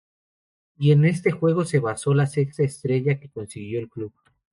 Pronounced as (IPA) /esˈtɾeʝa/